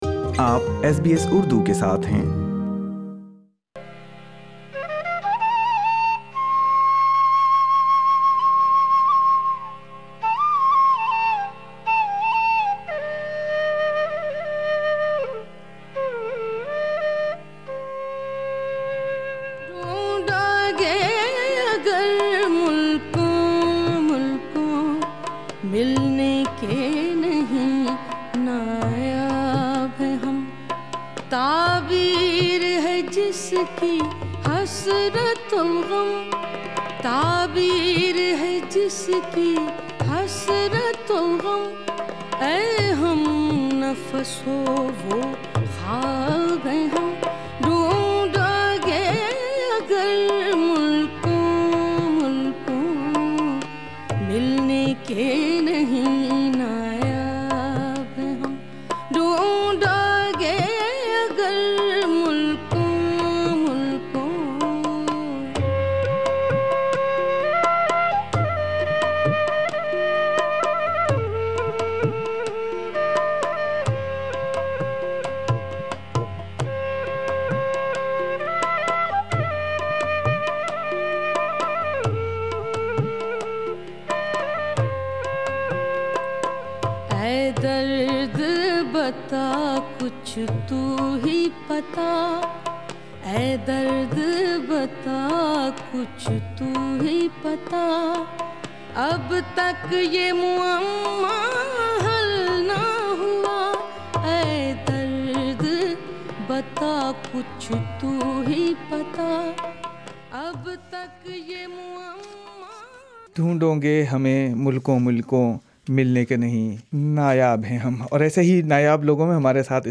آسٹریلیا ڈے پر اردو ٹاک بیک ۔ کیا آسٹریلیا ڈے کی تاریخ بدلنا چاہئے؟